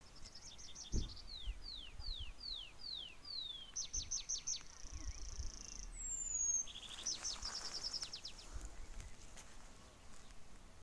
Tree Pipit, Anthus trivialis
Ziņotāja saglabāts vietas nosaukumsZvārtes Iezis
StatusSinging male in breeding season